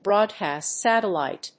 アクセントbróadcast sàtellite